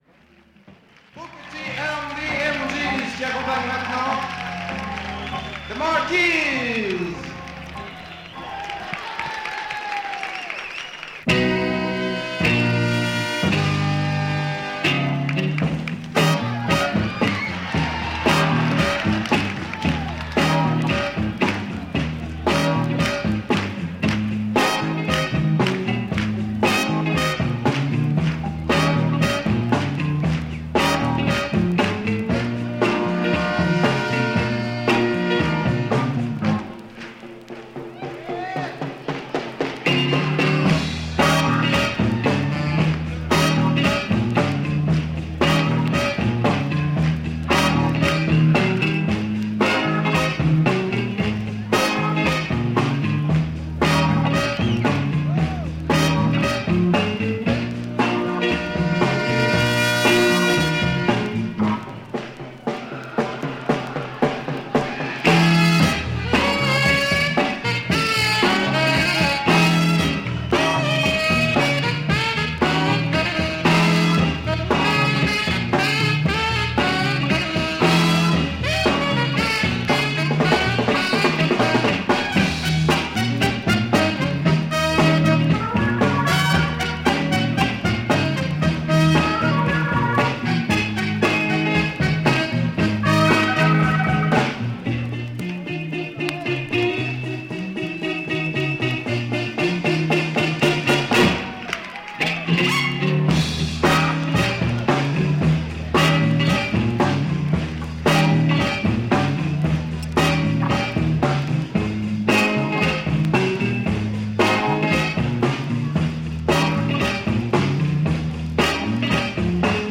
Soul French live recording album